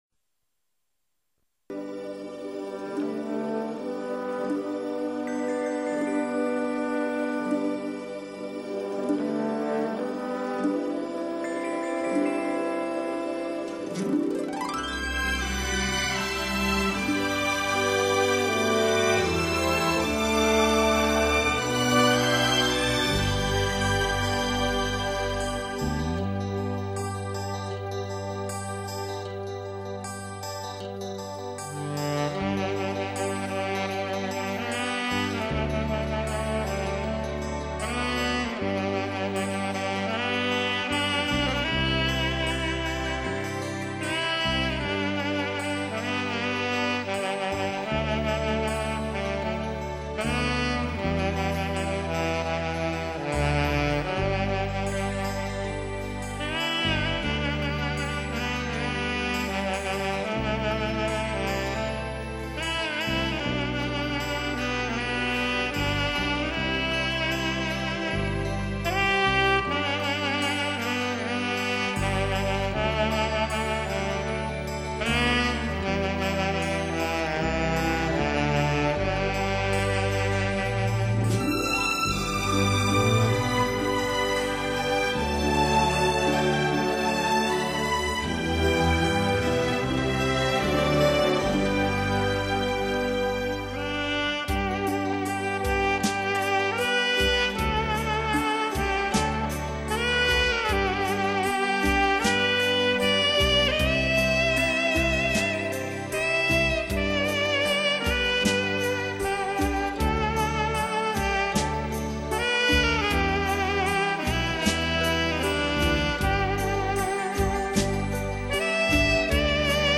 《友谊地久天长》纯音乐